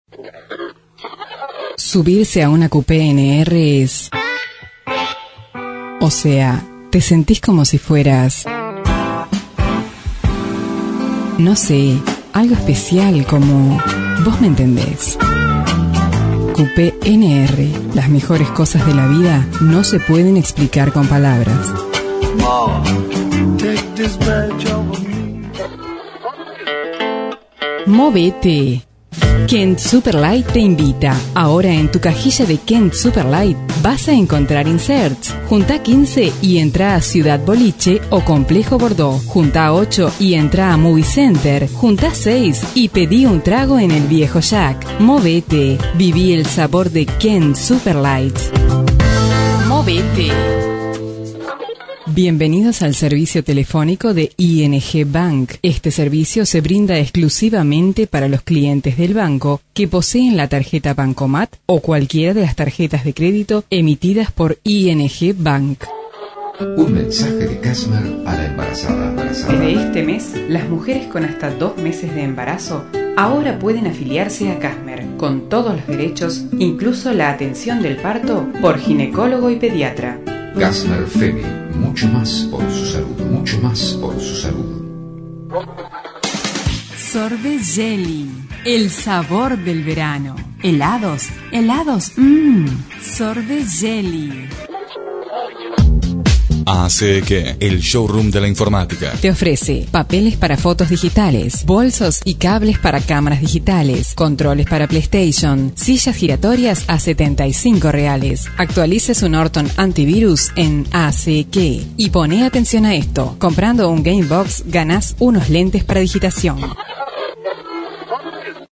voz melodiosa y suave,transmite confianza y tranquilidad.
kastilisch
Sprechprobe: Werbung (Muttersprache):
pleasant and melodious voice, trnsmite peace and security.